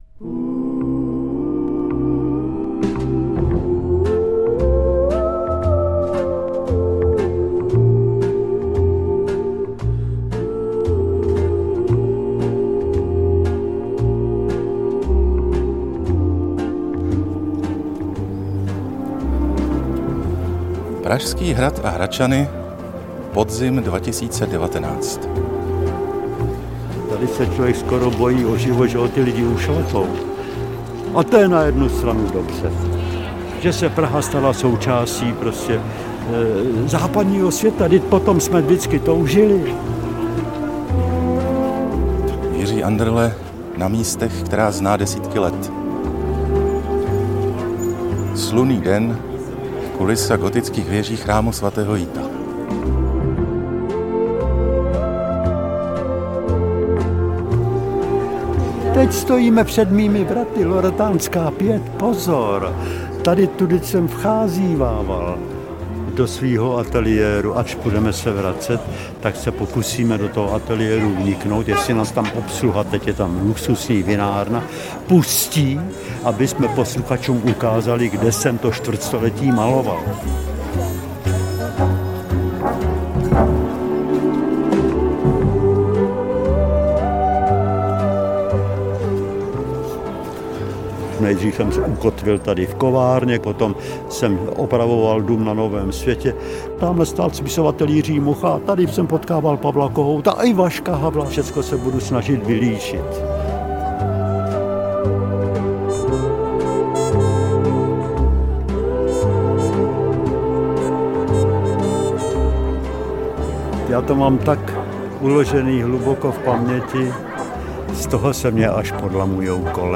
Zázraky na Hradčanech audiokniha
Ukázka z knihy
Zázraky na Hradčanech – audiokniha obsahuje rozhovor s akademickým malířem Jiřím Anderlem.… stále tu vidím dobrácké manžele Chabusovy, hluchého lampáře se psem Karlem, kopáče Foubíka pořád si brblajícího Ani mráčku na obláčku, staromódní manžele Engelmüllerovy, Bohumil Hrabal na to všechno říká, že má v hlavě jako po náletu, kterej nebyl…